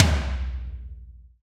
TOM TOM210QL.wav